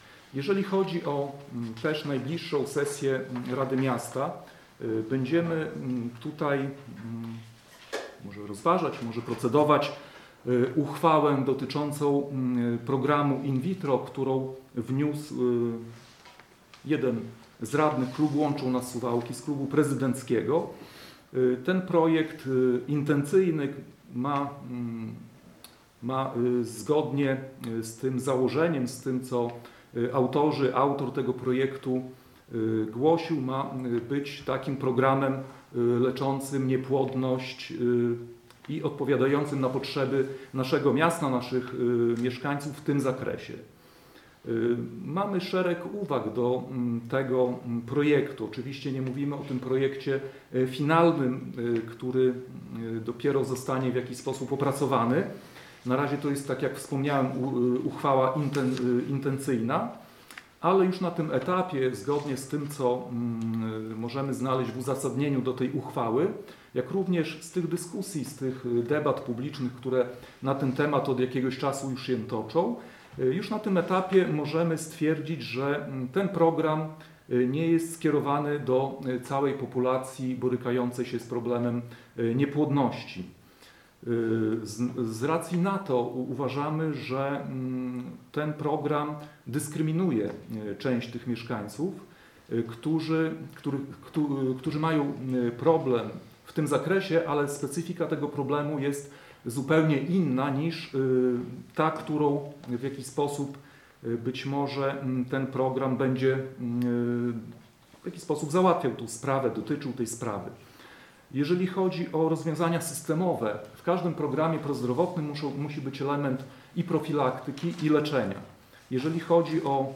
Radni Prawa i Sprawiedliwości, którzy w poniedziałek, 24 stycznia zorganizowali konferencję prasową, uważają, że w tej formie jest to program wykluczający część społeczności lokalnej, która boryka się z problemem bezpłodności.
Mówi Jacek Juszkiewicz, radny Prawa i Sprawiedliwości: